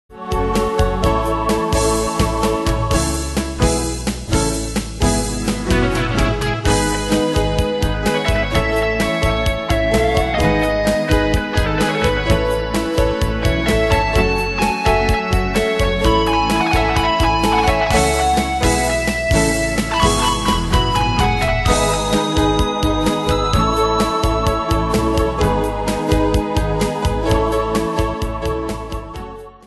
Style: PopFranco Ane/Year: 1991 Tempo: 128 Durée/Time: 3.11
Danse/Dance: PopRock Cat Id.
Pro Backing Tracks